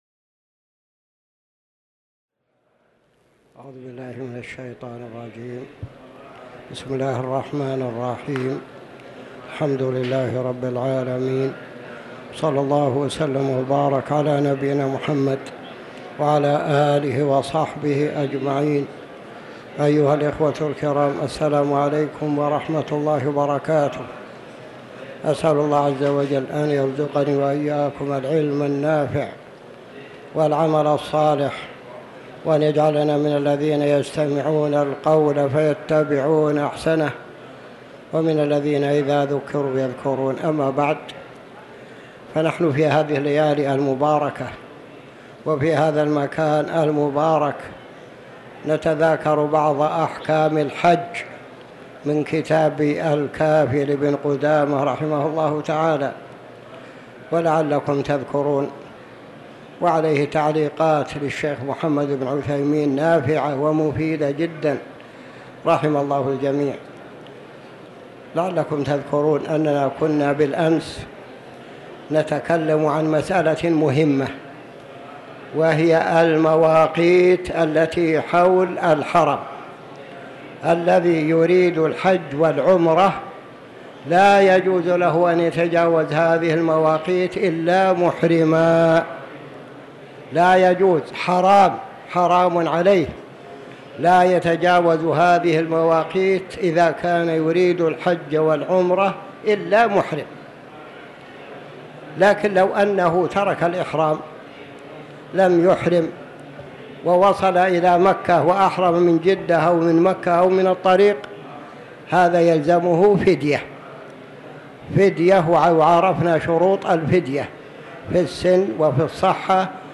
تاريخ النشر ٢٦ ذو القعدة ١٤٤٠ هـ المكان: المسجد الحرام الشيخ